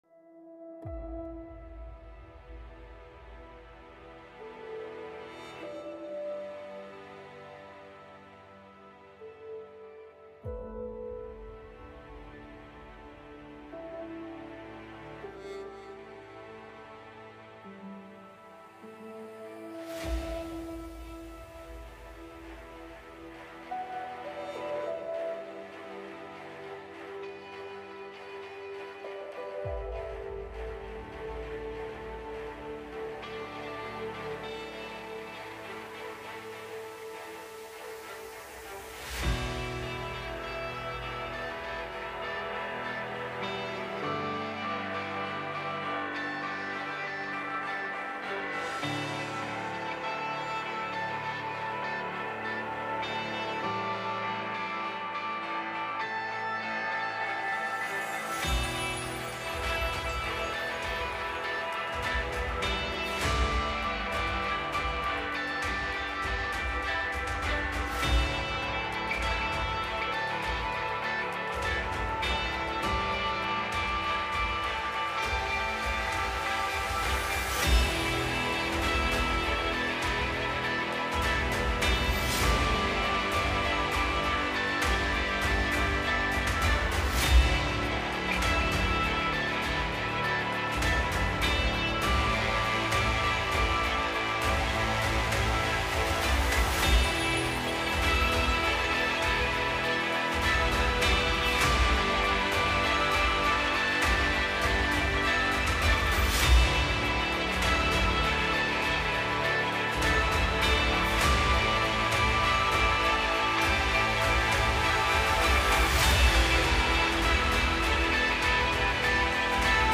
Singing-Stones-Sermon-4.13.25.m4a